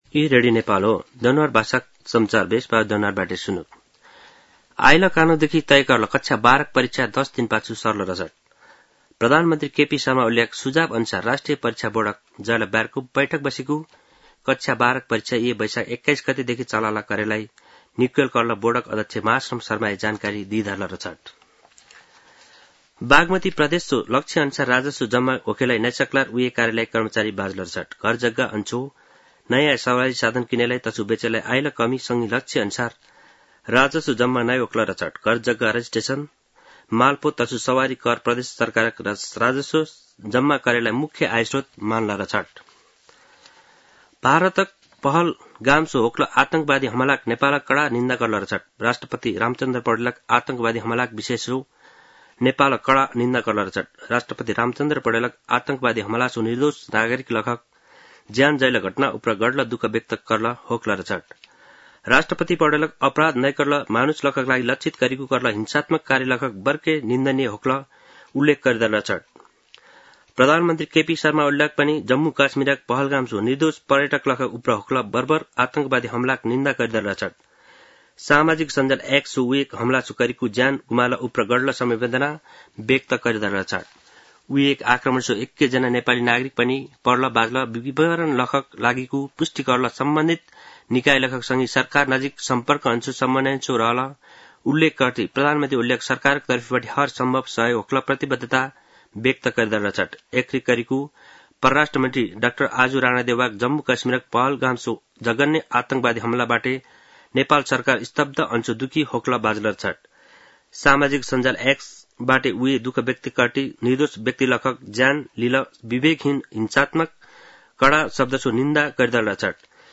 दनुवार भाषामा समाचार : १० वैशाख , २०८२
danuwar-news-1-9.mp3